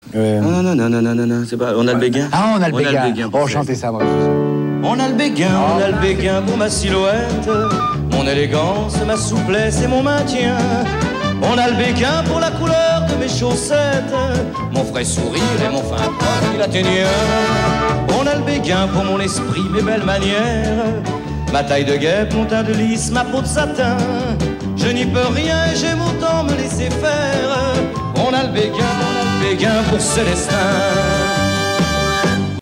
INEDITS SOLO TV/RADIO